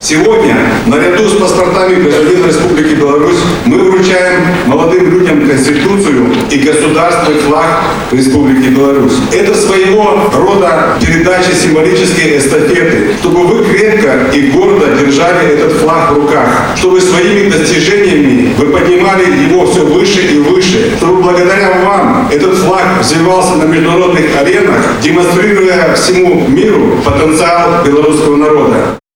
Затем в городском Доме культуры состоялось торжество и праздничный концерт, посвященный Дню Конституции. С приветственным словом к собравшимся обратился помощник Президента Беларуси — инспектор по Брестской области Валерий Вакульчик.